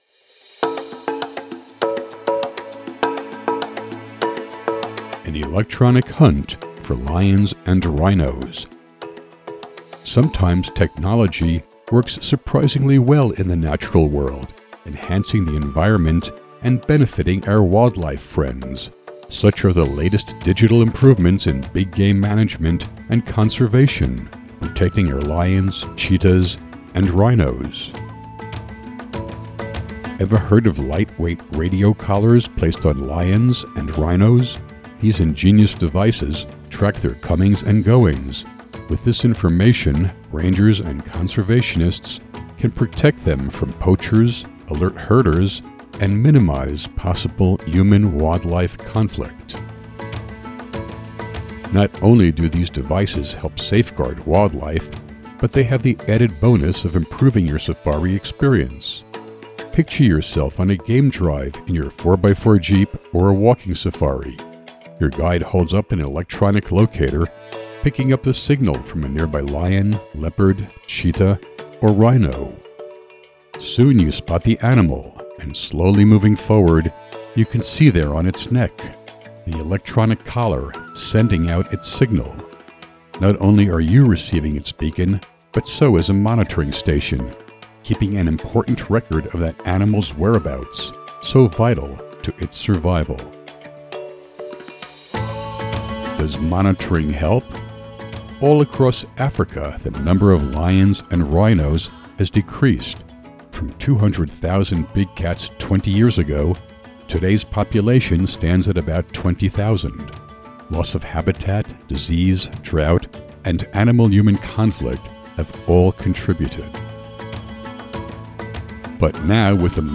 Listen to an audio version of this blog post!